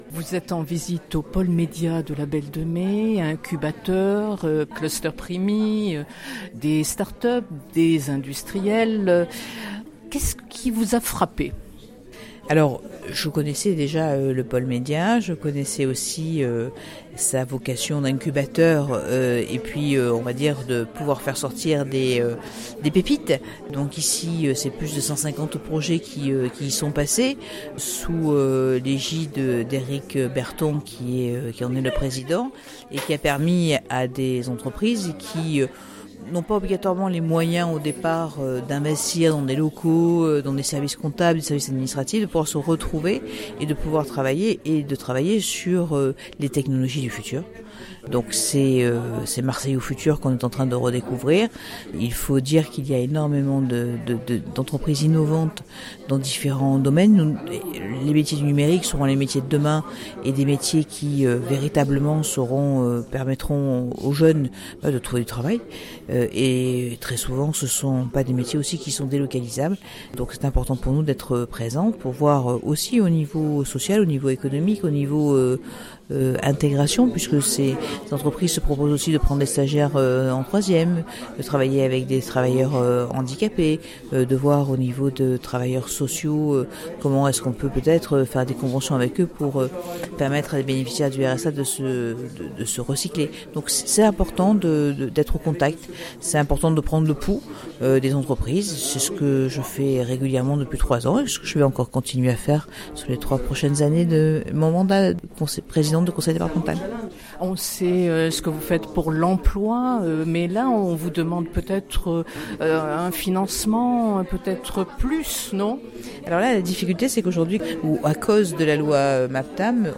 » Entretien.